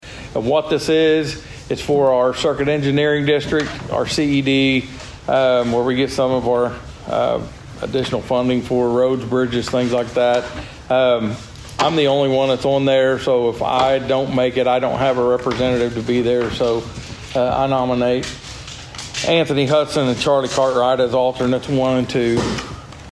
District two commissioner Steve Talburt explains why it is important to have two alternates